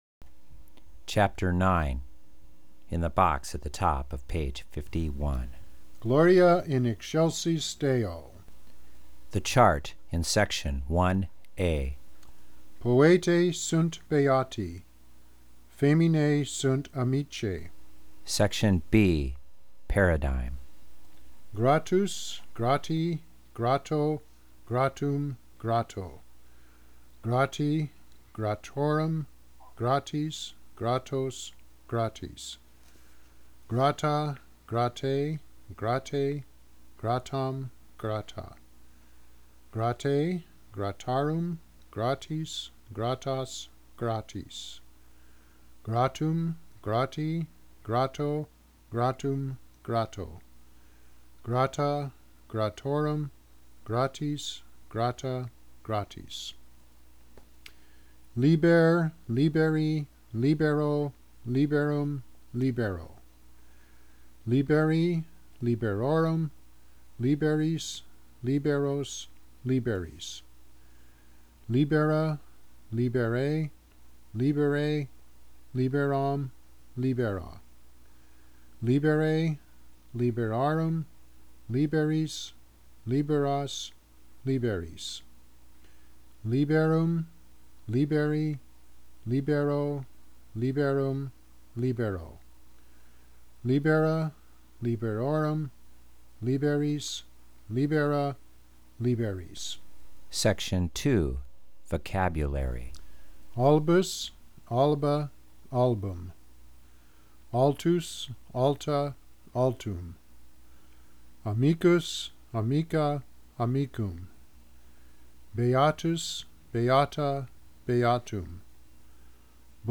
Free Pronunciation// Download